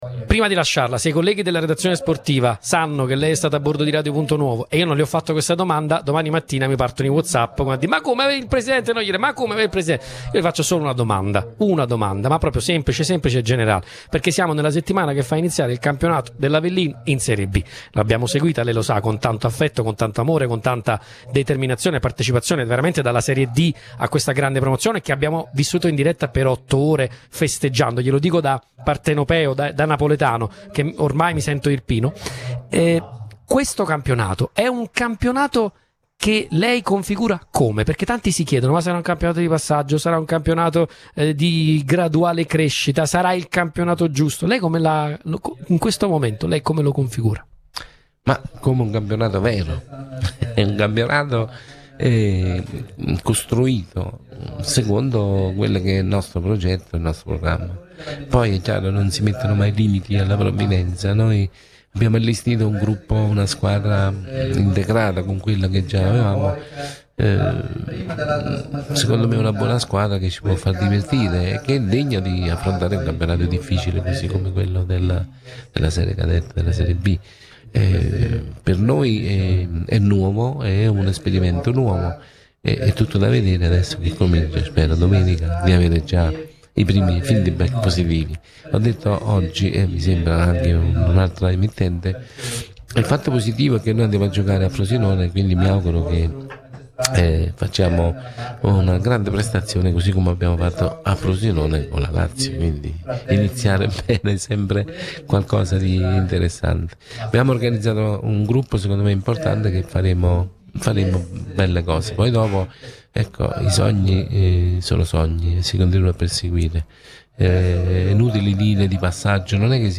D’Agostino a Radio Punto Nuovo, in diretta da Montefalcione:
Parole cariche di ambizione e passione, pronunciate nel corso dell’anteprima dell’evento “Per le vie del borgo”, trasmesso in diretta su Radio Punto Nuovo.
La serata, organizzata dalla Pro Loco di Montefalcione, ha visto protagonista il sindaco e presidente D’Agostino, che ha ribadito il legame profondo tra sport, territorio e comunità.